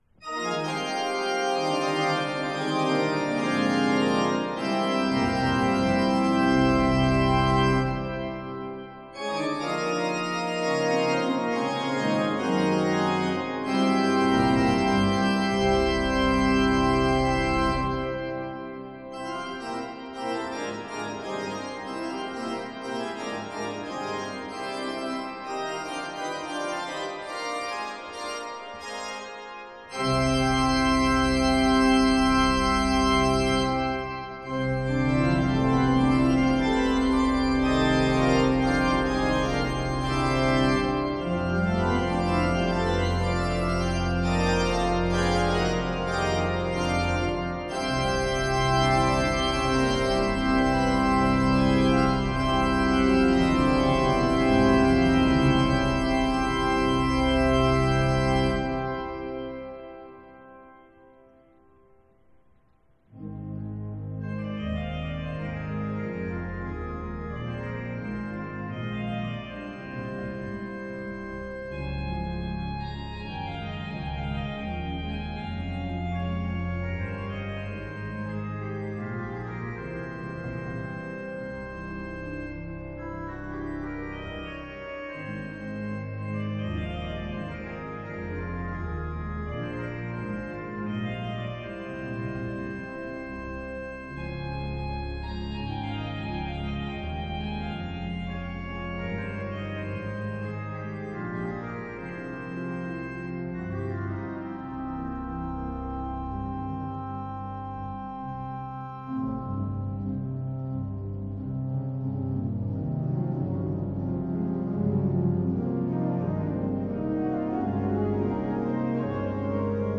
orgue Église du Gésù, Montréal, Québec.